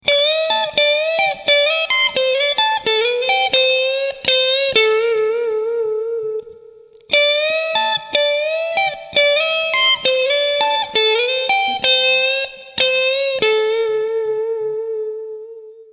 Fraseggio blues 12
Sempre nelle posizioni alte, alterniamo dei bending a degli slide, ottenendo così una sonorità molto country, comunque tipica anche nel fraseggio blues.